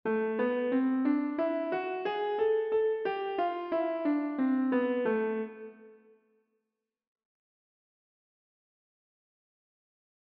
＜Ａメロディックマイナースケール